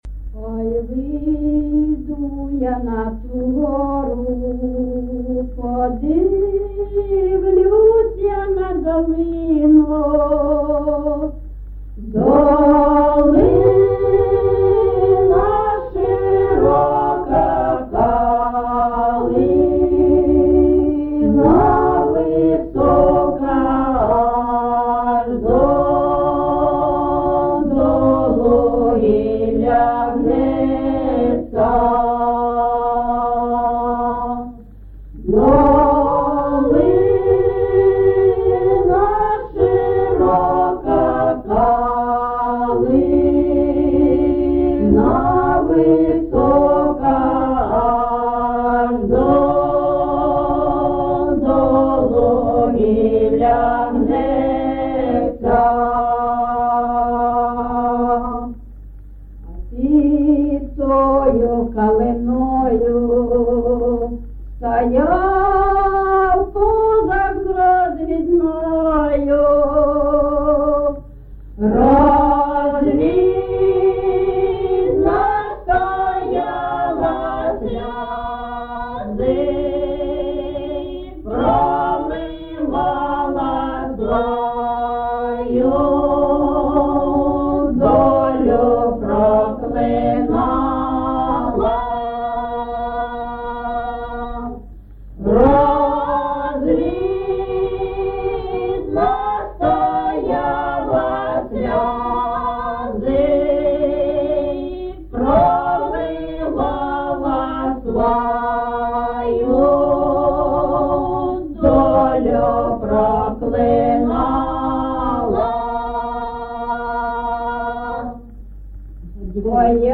ЖанрПісні з особистого та родинного життя
МотивНещаслива доля, Родинне життя, Журба, туга
Місце записум. Єнакієве, Горлівський район, Донецька обл., Україна, Слобожанщина